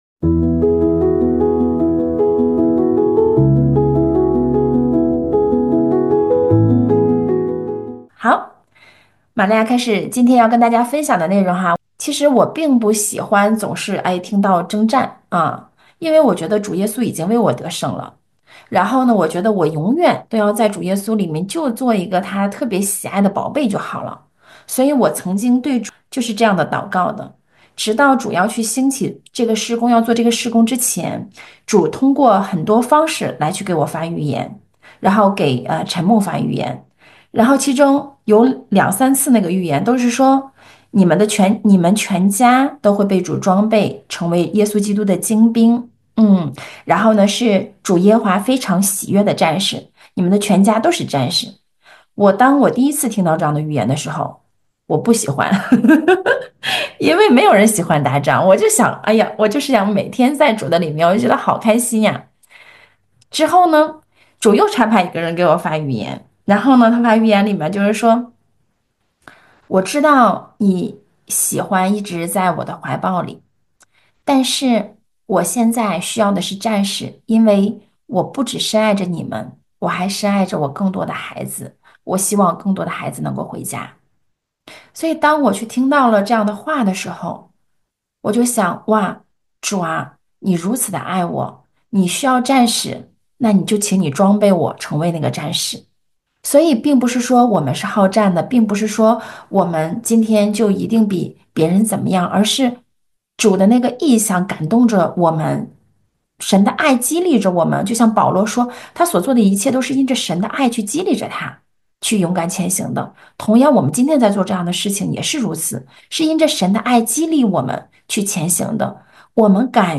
主日讲道